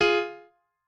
piano8_20.ogg